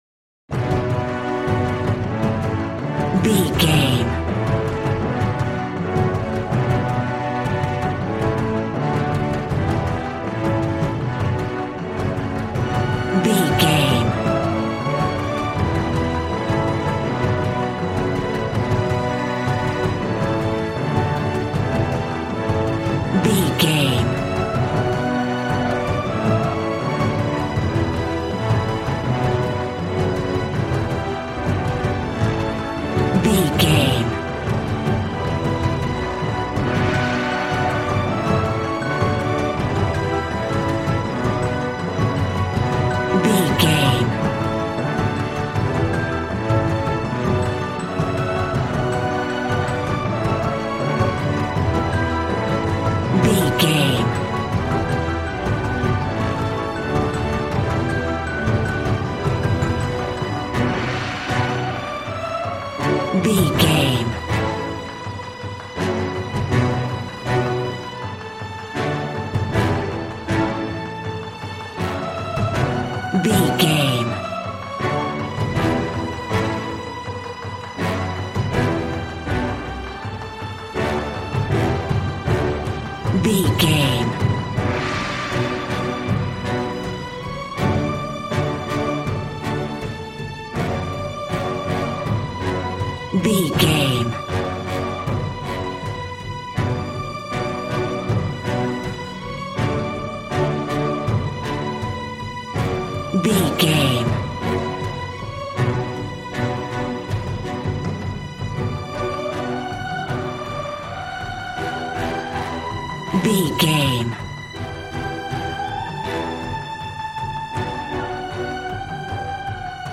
Regal and romantic, a classy piece of classical music.
Aeolian/Minor
regal
cello
double bass